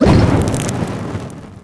barkeep_throw_ulti_01.wav